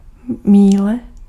Ääntäminen
US : IPA : [maɪl] UK : IPA : /maɪ̯l/ US : IPA : /maɪ̯l/